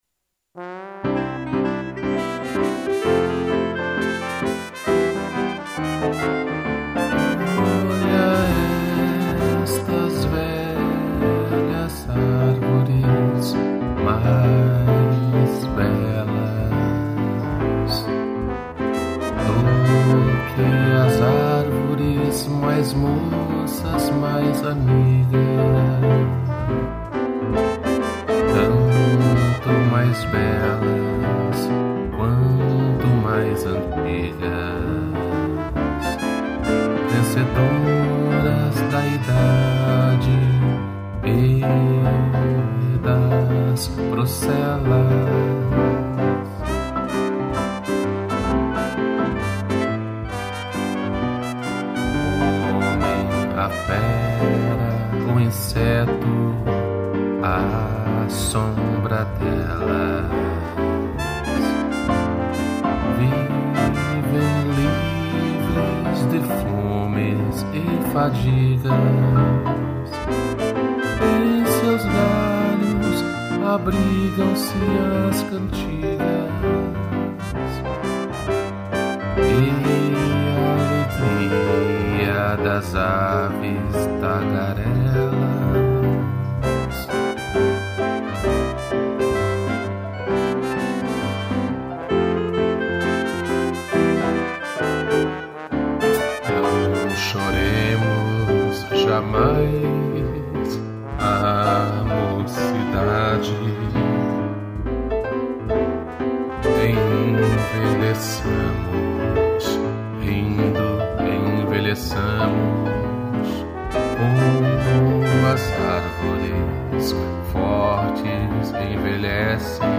2 pianos, trompete, trombone e clarinete